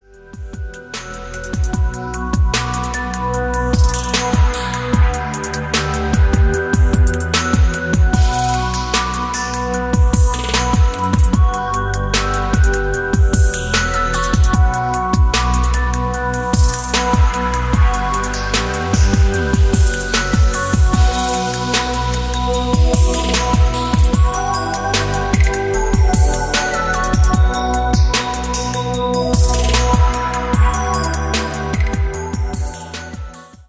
• Качество: 192, Stereo
атмосферные
Electronic
спокойные
красивая мелодия
релакс
Trap
Chill Trap
расслабляющие
chillwave